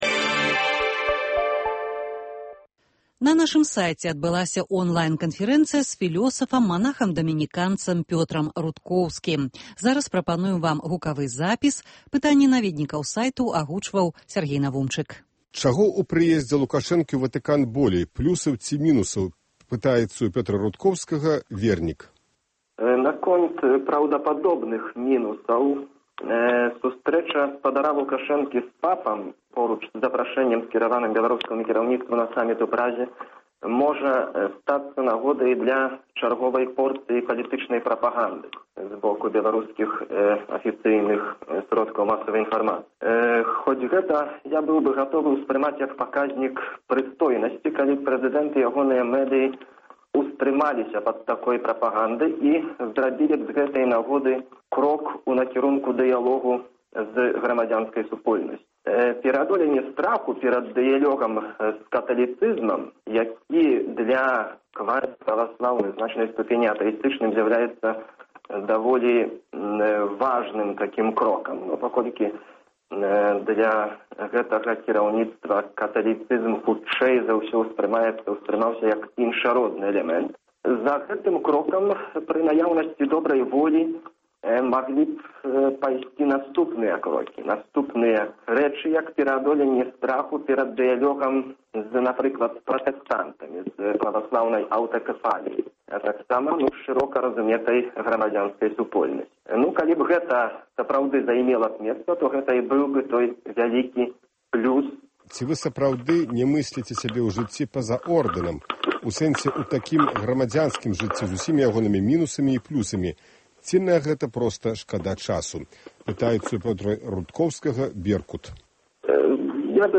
Онлайн канфэрэнцыя